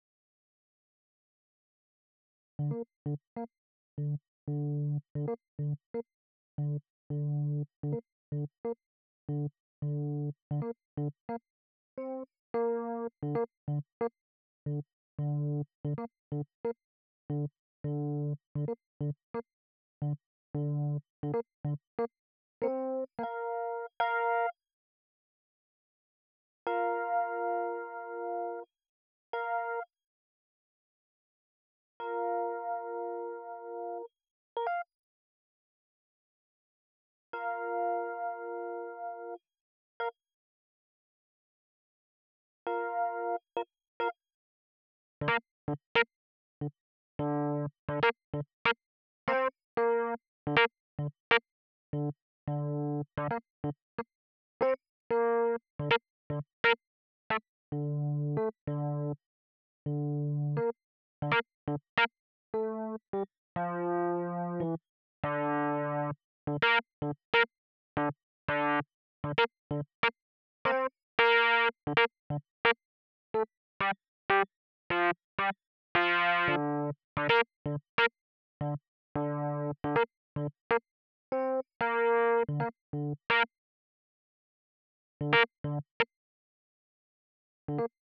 90 BPM
epiano Wide Suitcase_2 68385_Wide Suitcase_2.wav